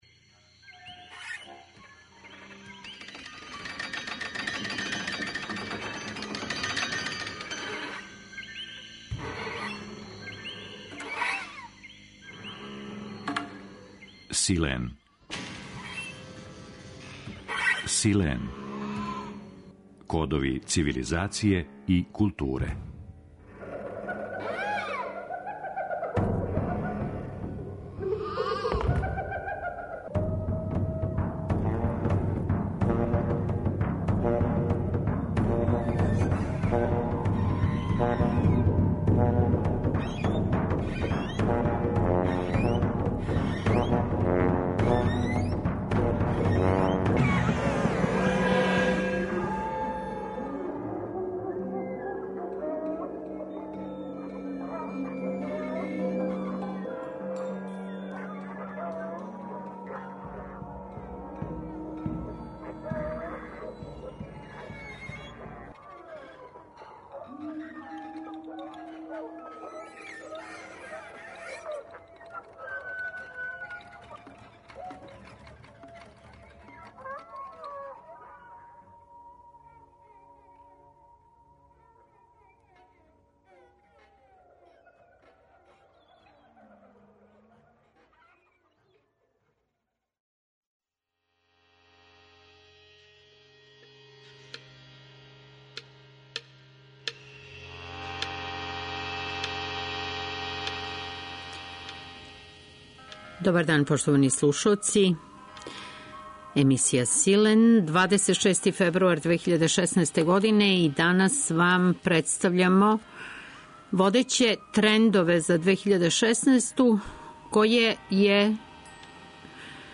Саговорник емисије 'Силен' је Џералд Селенте